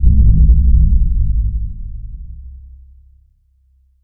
Low End 24.wav